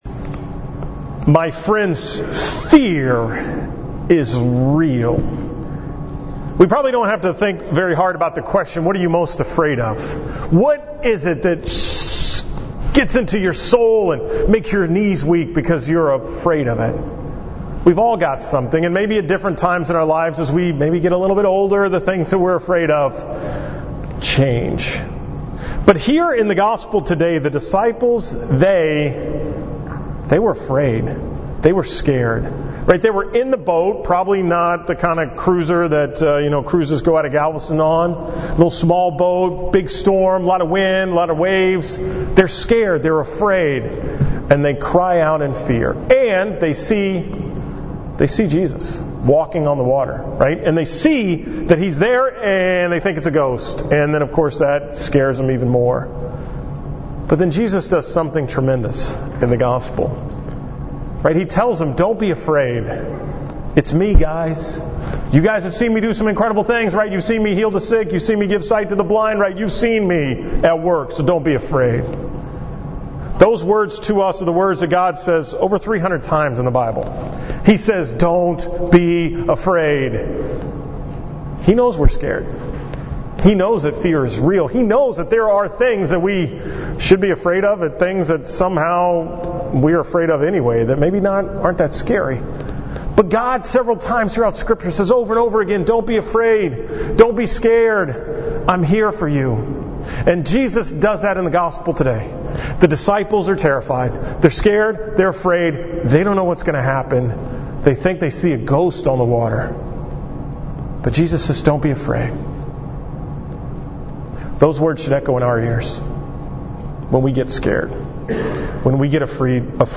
From Mass at Sacred Heart in Pattison on the 19th Sunday in Ordinary Time